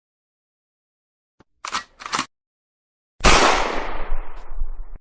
Gun Reload & Gun Shot Free High Quality Sound Effect
Gun_Reload__Gun_Shot_Free_High_Quality_Sound_Effect.mp3